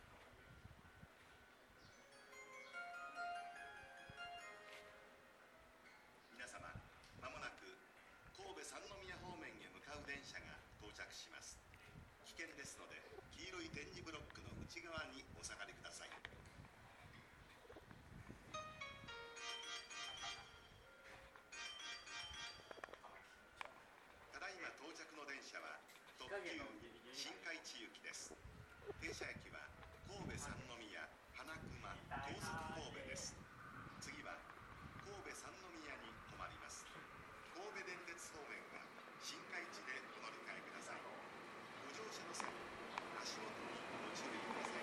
この駅では接近放送が設置されています。
１号線HK：阪急神戸線
接近放送特急　新開地行き接近放送です。